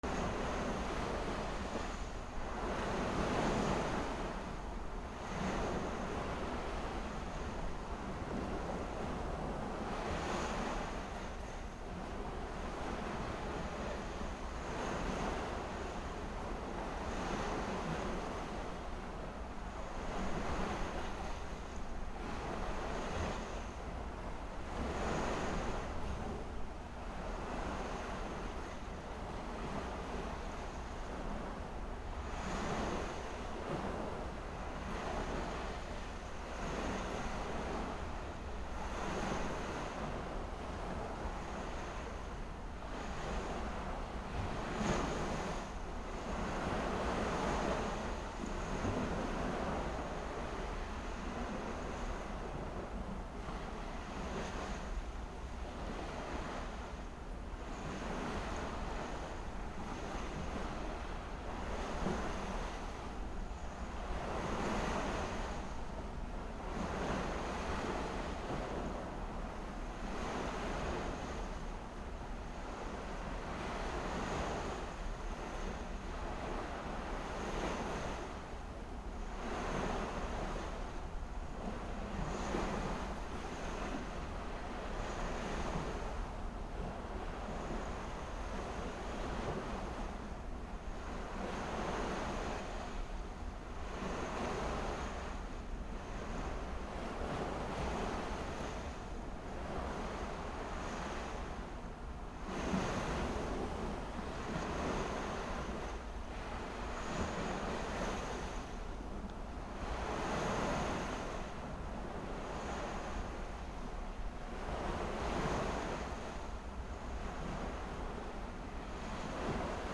/ B｜環境音(自然) / B-10 ｜波の音 / 波の音
波の音 さらにオフ
サー